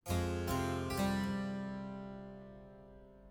Sound-Objects
Electroacoustic
Experimental